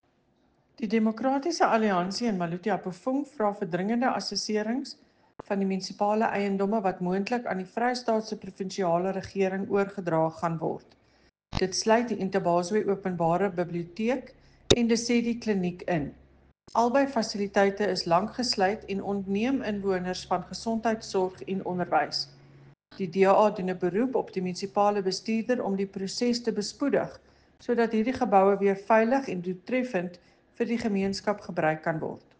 Afrikaans soundbite by Cllr Eleanor Quinta, and